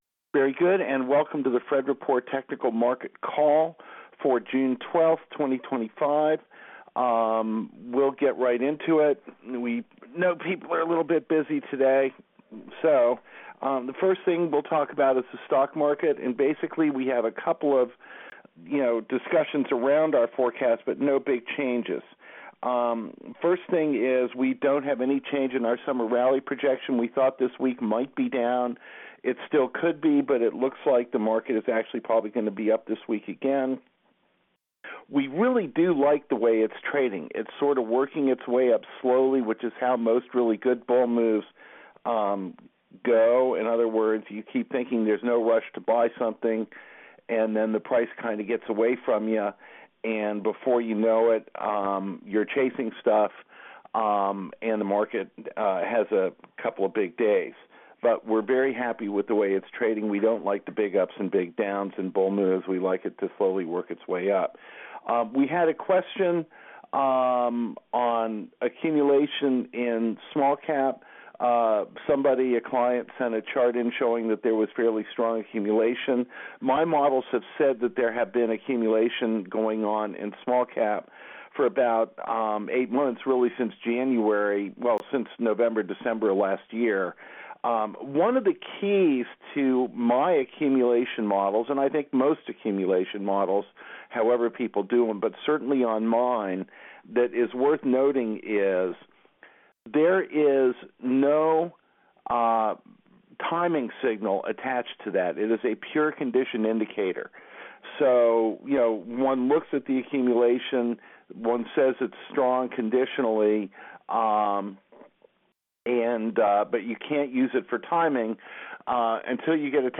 Conference Call Recording: